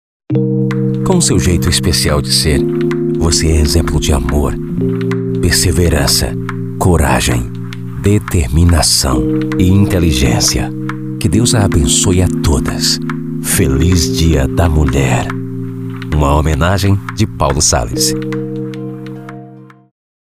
Chamada - Suave/Emotiva: